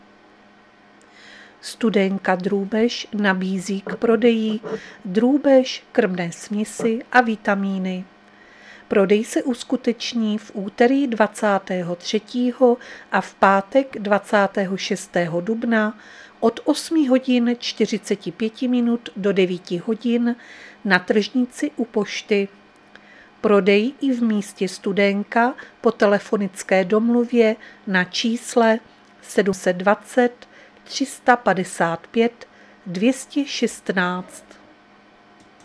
Záznam hlášení místního rozhlasu 22.4.2024
Zařazení: Rozhlas